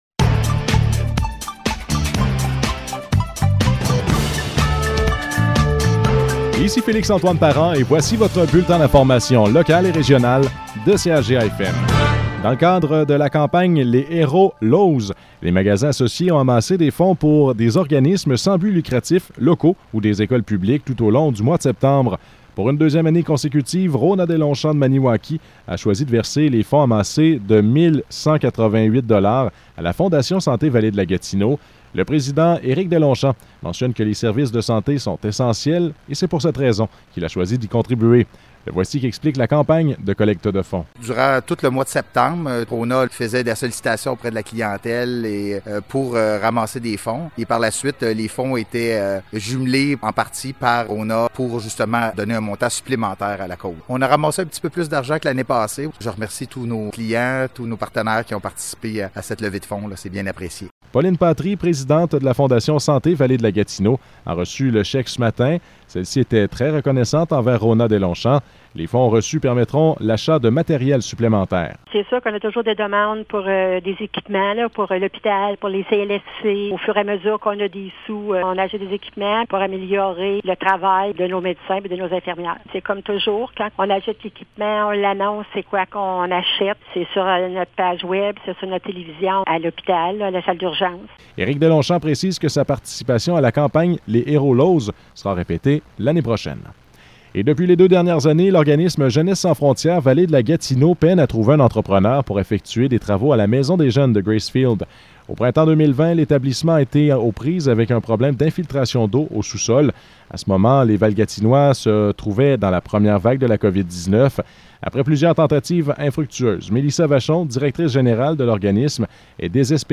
Nouvelles locales - 4 novembre 2021 - 12 h